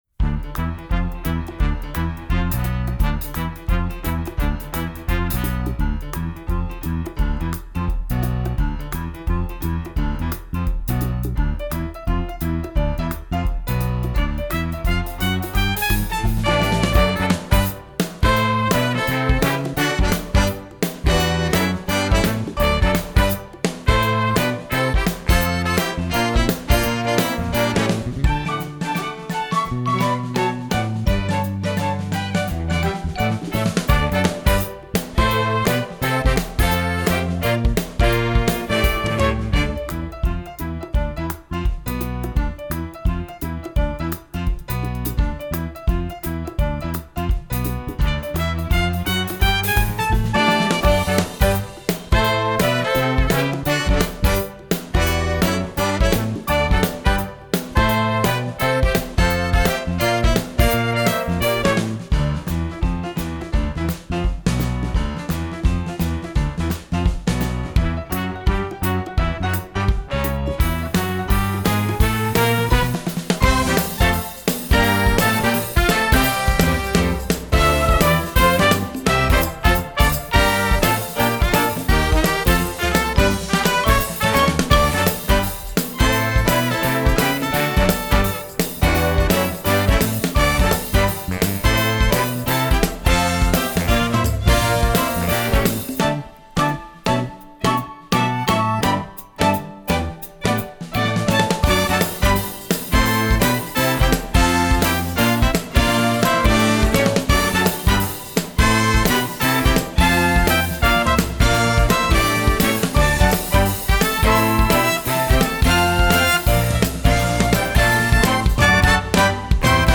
Performance Accompaniment Tracks: